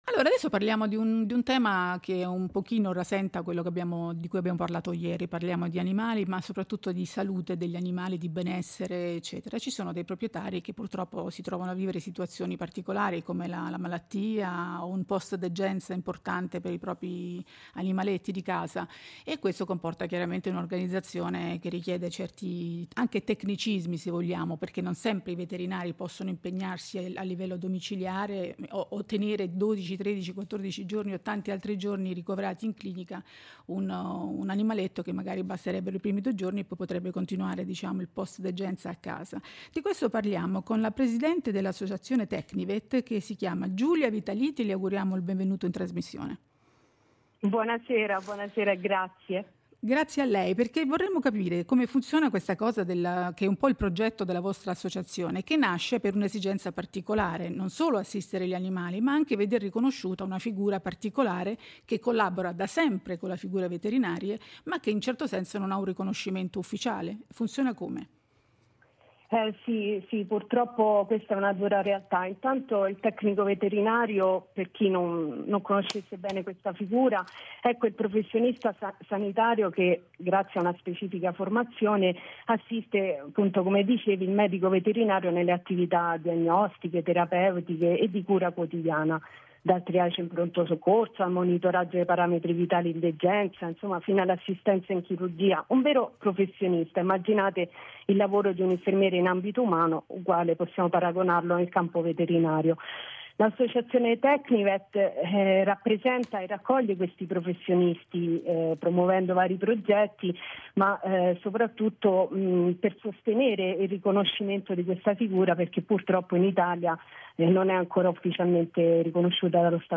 SU RADIO RAI 1 SI PARLA DI NOI!